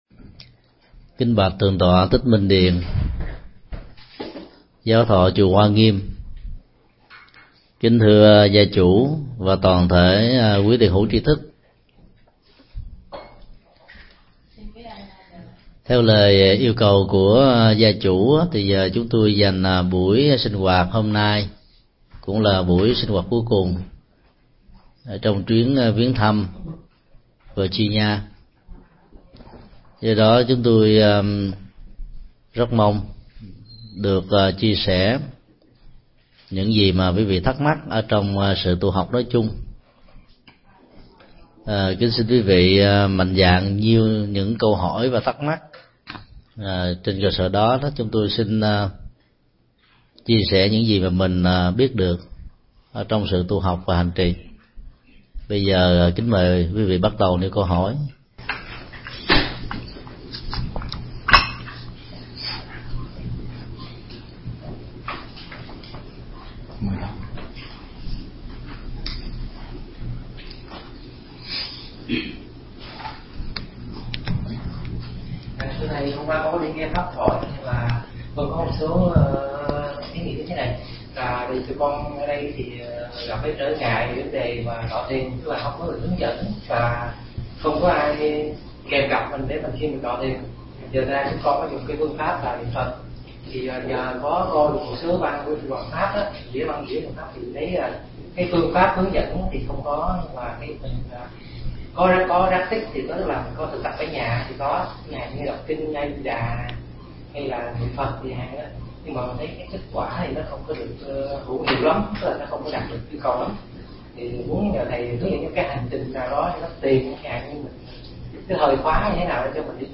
Mp3 Pháp Thoại Thực tập và hạnh phúc – Thượng Tọa Thích Nhật Từ giảng tại Đạo tràng Washington DC, ngày 13 tháng 8 năm 2007